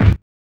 CLATTER.wav